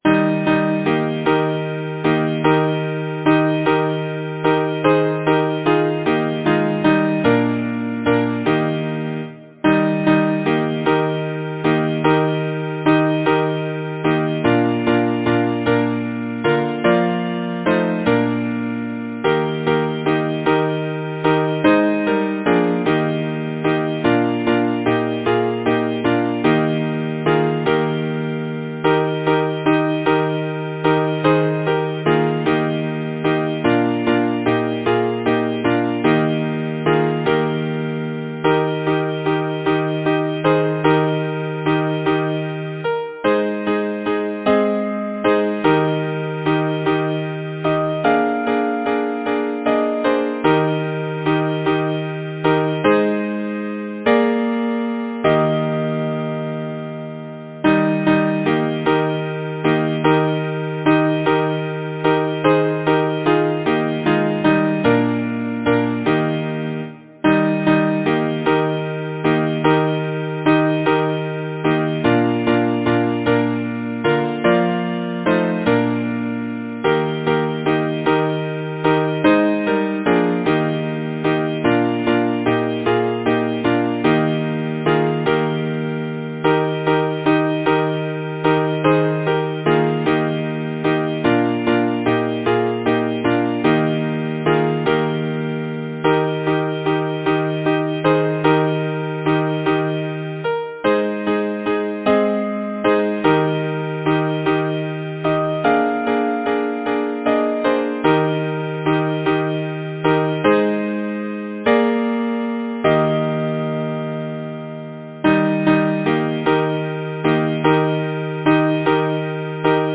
Title: Canadian Boat Song Composer: Thomas William Hubbard Lyricist: Thomas Moore Number of voices: 4vv Voicing: SATB Genre: Secular, Partsong
Language: English Instruments: A cappella